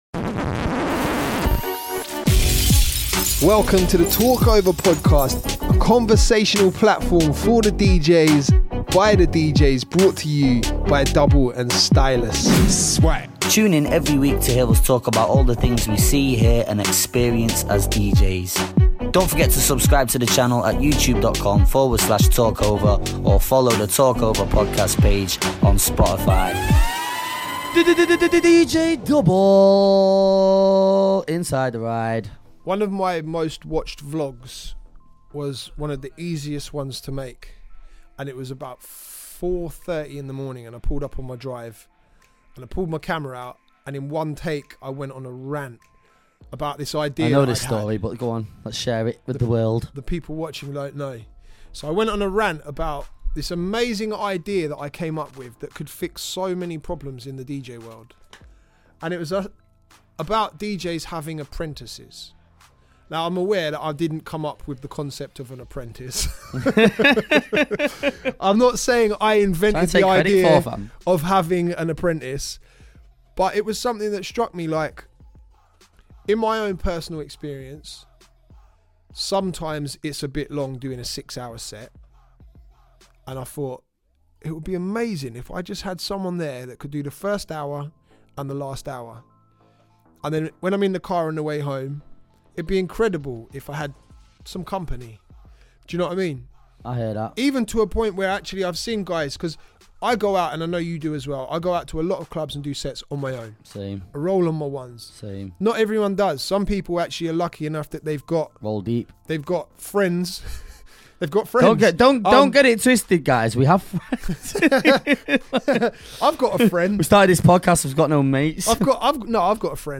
two DJs from the UK & we've decided that there needs to be an outlet for various topics relating to the DJ world…